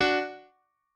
piano2_15.ogg